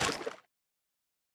Minecraft Version Minecraft Version snapshot Latest Release | Latest Snapshot snapshot / assets / minecraft / sounds / mob / strider / step5.ogg Compare With Compare With Latest Release | Latest Snapshot
step5.ogg